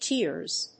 /tirz(米国英語), ti:rz(英国英語)/